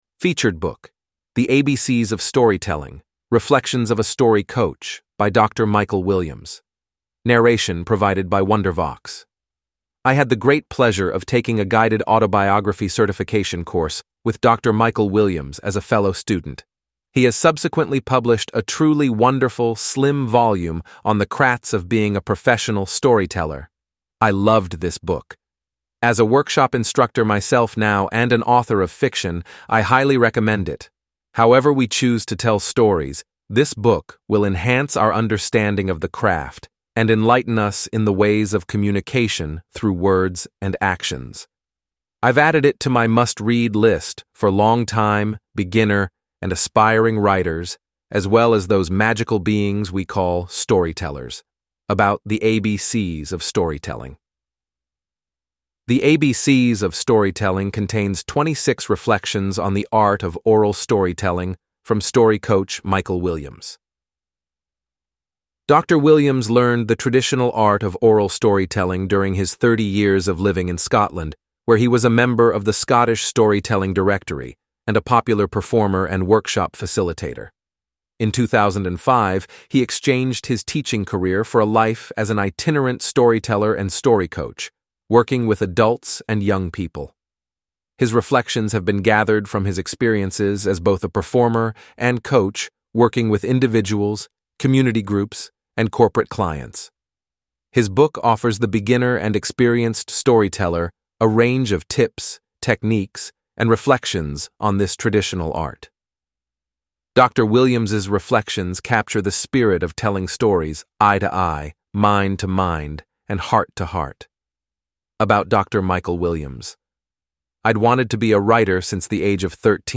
Audiobook for ‘A Marriage Below Zero’ by Alan Dale Now Available!
Listen to a 20 minute sample – Narration provided by Wondervox